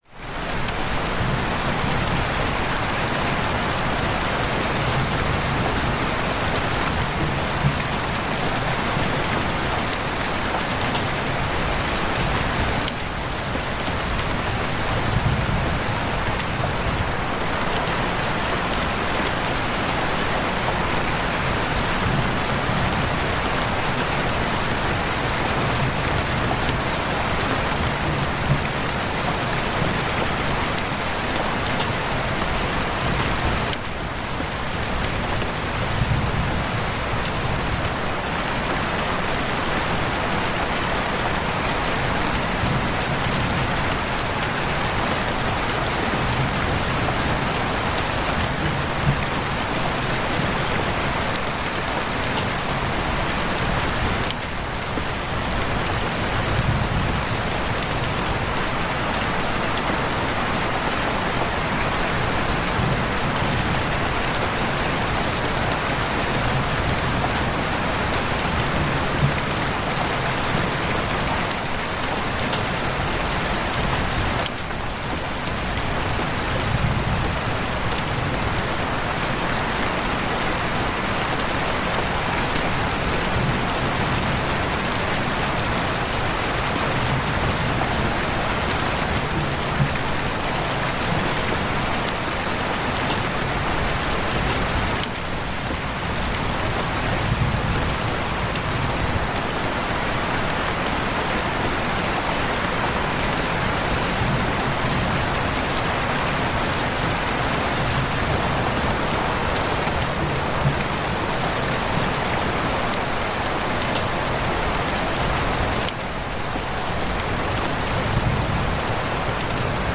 Pluie forestière résonnant sur surfaces proches pour une immersion profonde et enveloppante